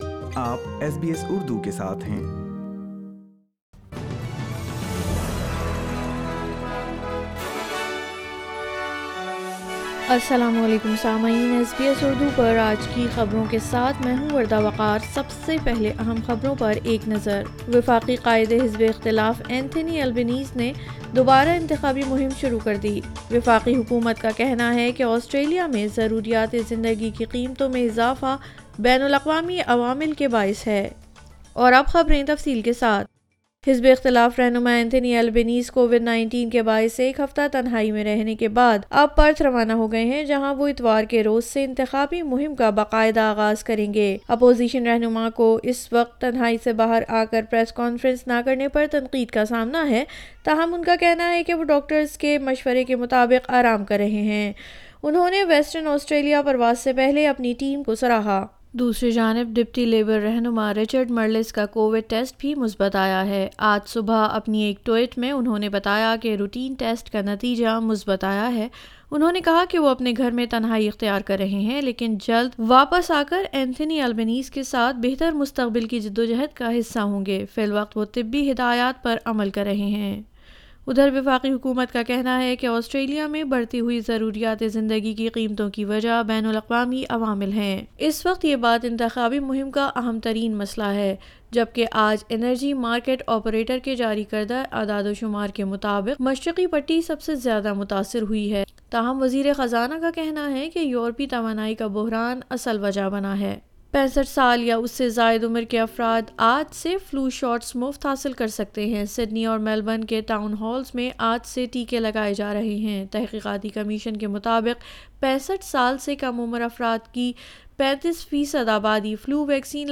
SBS Urdu News 29 April 2022